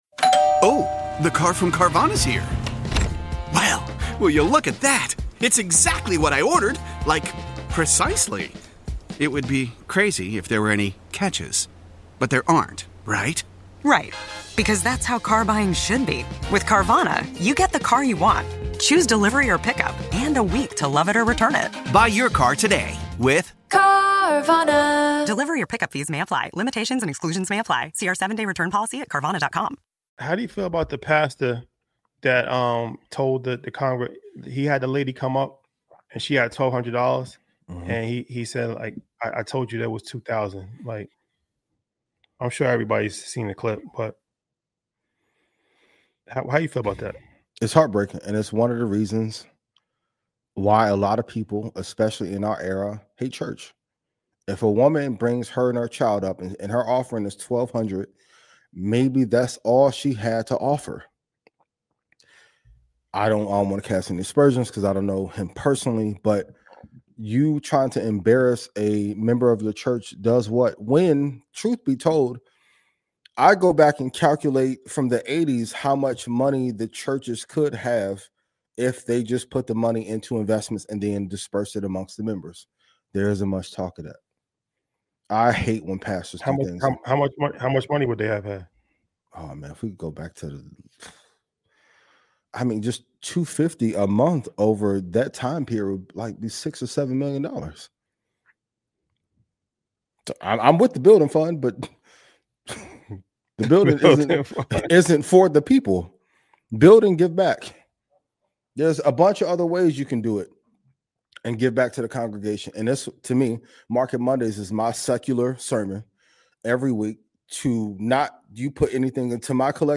This thoughtful discussion brings up hard questions: How should church leaders truly serve their congregation?